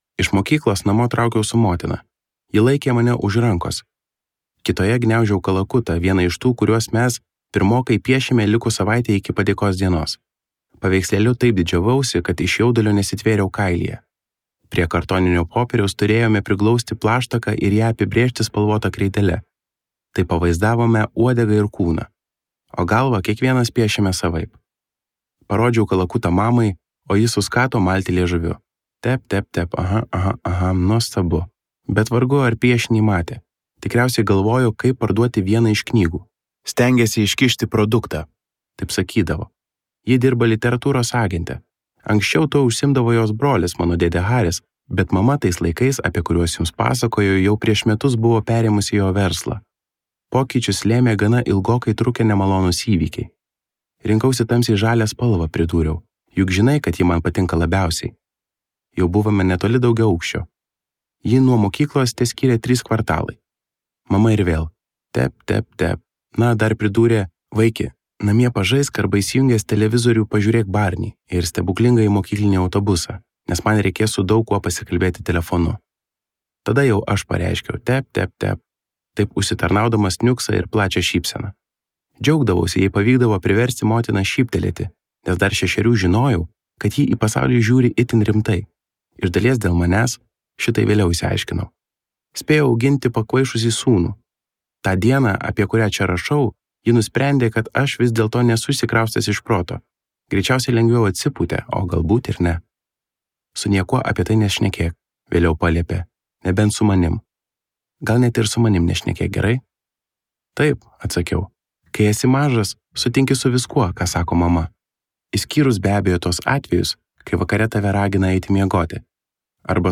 Vėliau | Audioknygos | baltos lankos